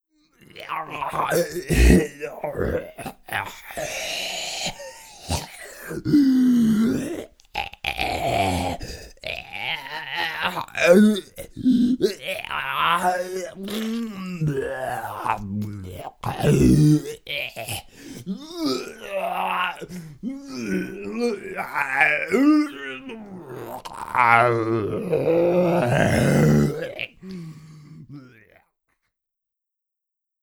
zombie_struggle.wav